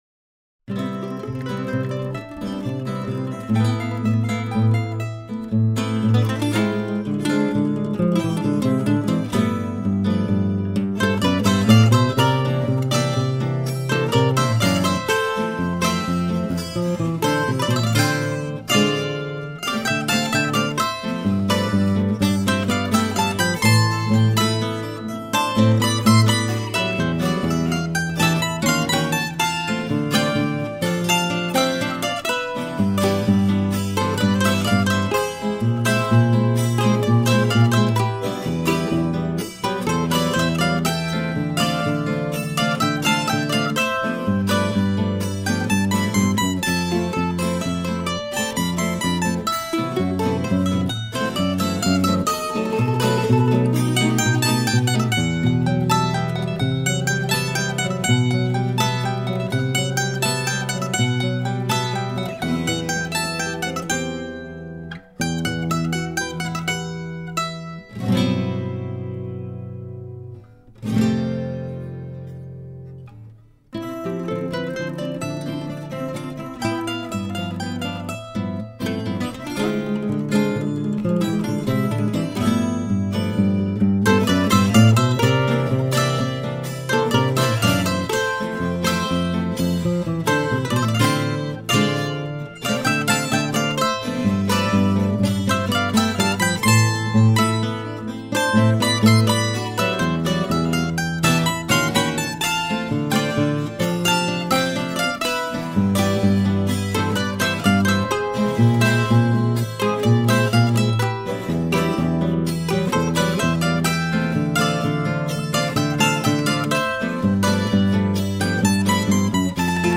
Bambuco
Cuarteto Instrumental
Colombiana